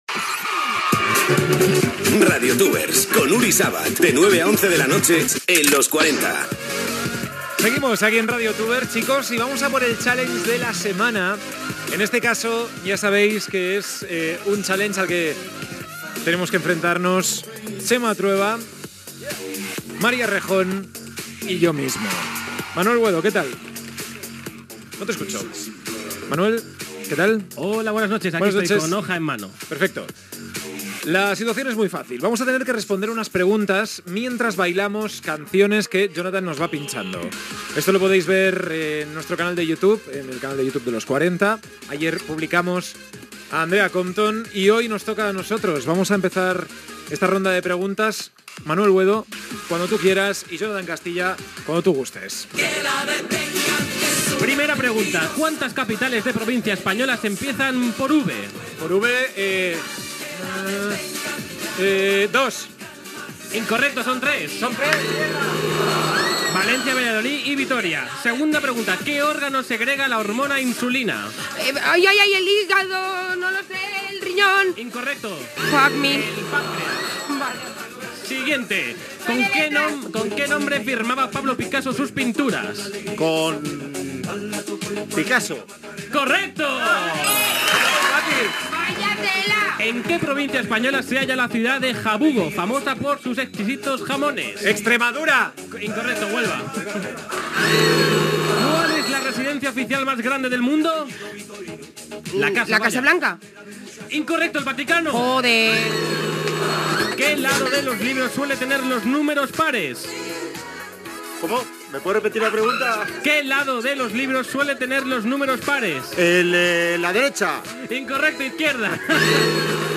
Indicatiu del programa i "Challenge de la semana". Repte de contestar preguntes mentre s'està ballant
Entreteniment